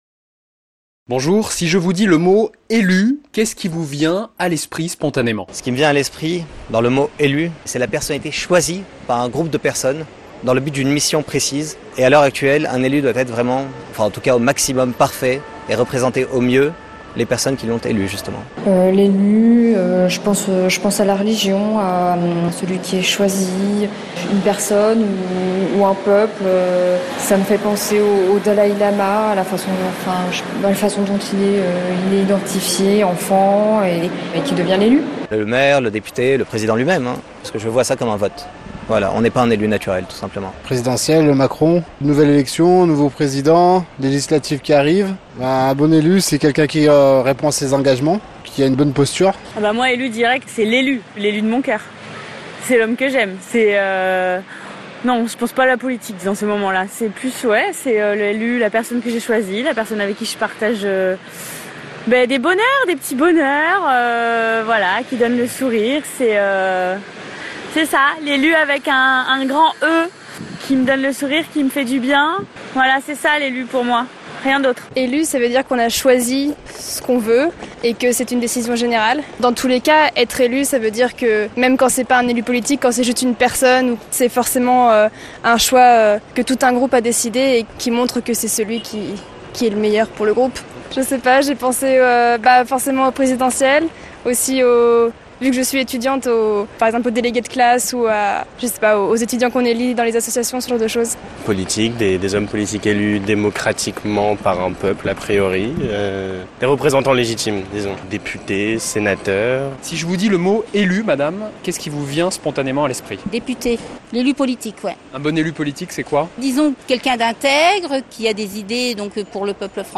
Micro-trottoir_Elu.mp3